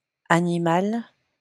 wymowa:
IPA[aniˈmal] ?/i; lm [aniˈmo]